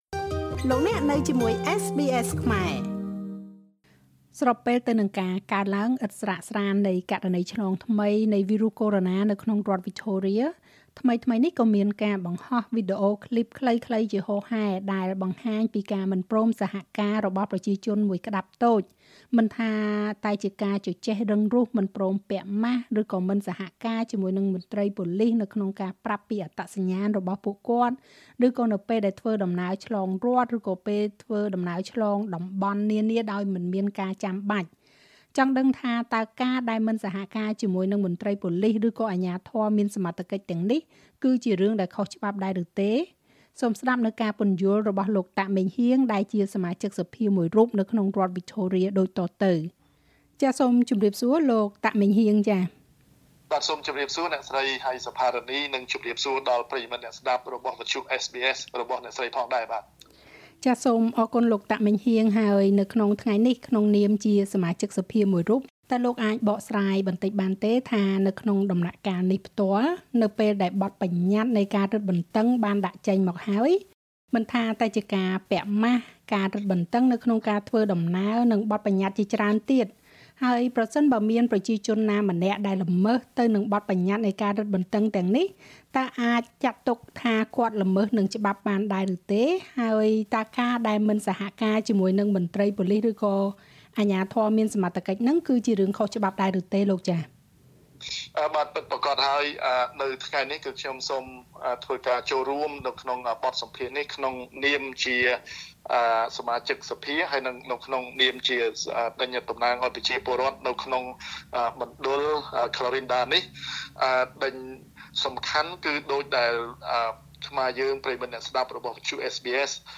សូមស្តាប់ការពន្យល់របស់លោក តាក ម៉េងហ៊ាង ដែលជាសមាជិកសភាមួយរូបនៅក្នុងរដ្ឋវិចថូរៀ។
interview_heang_tak_-_law_offence_or_regulation.mp3